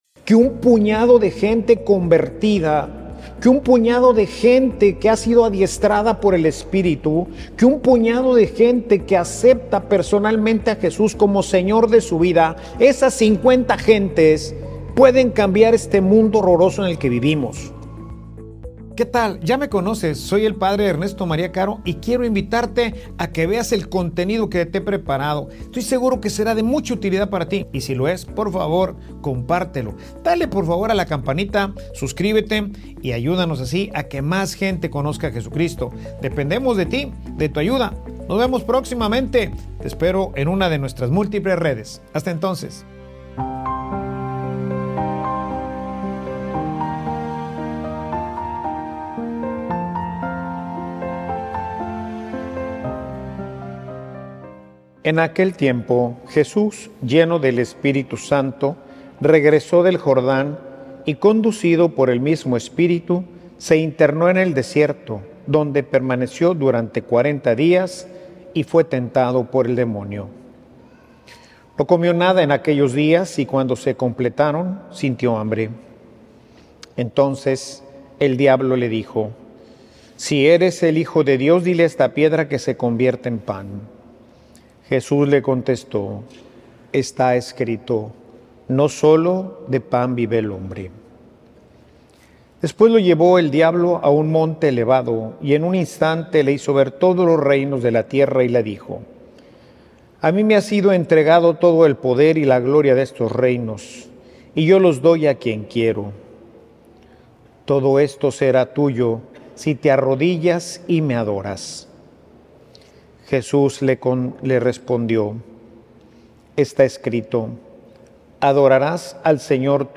Homilia_El_desierto_revela_lo_peor_de_ti.mp3